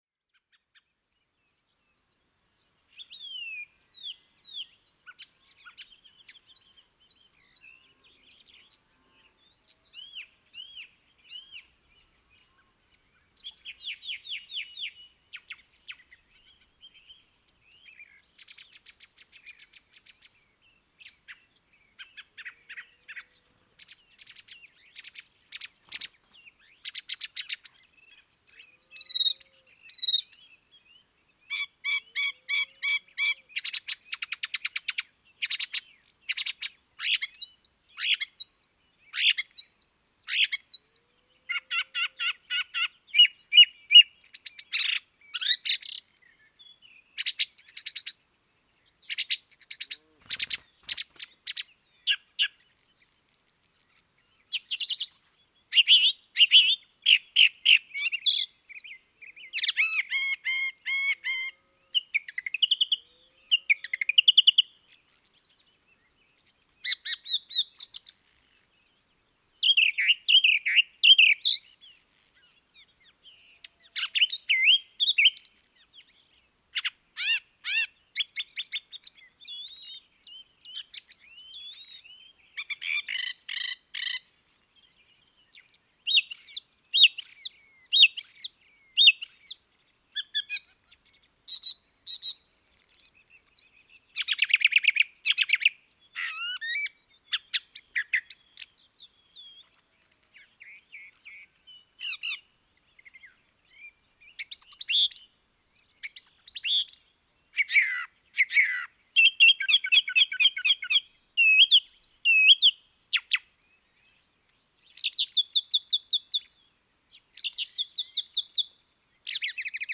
Zanglijster geluid
• De zanglijster, of Turdus philomelos, staat bekend om zijn luide, trage zang met veel herhalingen.
• Hun unieke ‘tsip’ roep is hoog en scherp, en dient vaak als waarschuwing.
Zijn zang klinkt luid en traag door de lucht, vaak met een pauze tussen de herhalingen. Dit unieke patroon, waarbij een geluid driemaal wordt herhaald, maakt het makkelijk om de zanglijster van andere vogels te onderscheiden.
Je hoort haar luide, trage zang met kenmerkende herhalingen. Ook de scherpe “tsip” roep is duidelijk te beluisteren.